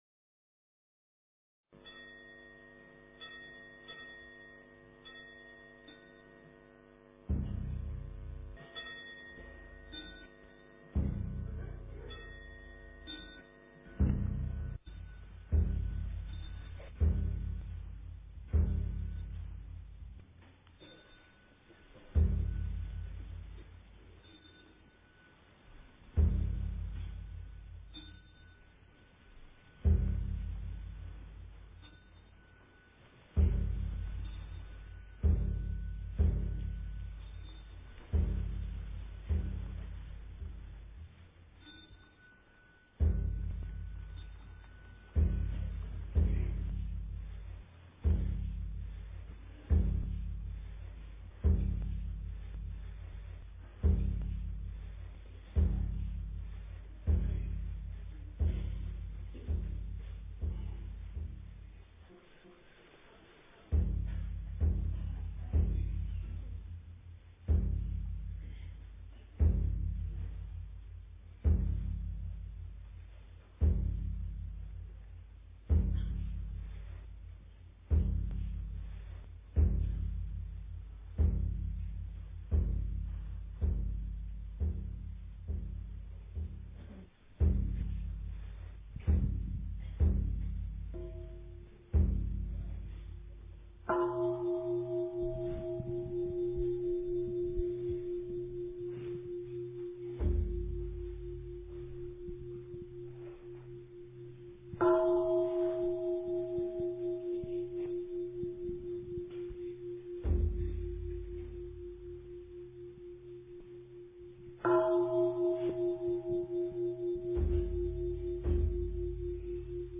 晚课一--普寿寺 经忏 晚课一--普寿寺 点我： 标签: 佛音 经忏 佛教音乐 返回列表 上一篇： 晚课-莲池赞 佛说阿弥陀经--未知 下一篇： 八十八佛大忏悔文--未知 相关文章 南无观世音菩萨--四音调唱赞 南无观世音菩萨--四音调唱赞...